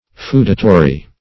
feudatory - definition of feudatory - synonyms, pronunciation, spelling from Free Dictionary
Feudatory \Feu"da*to*ry\, n.; pl. Feudatories.